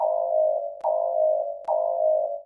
Attack.wav